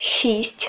Added Ukrainian numbers voice files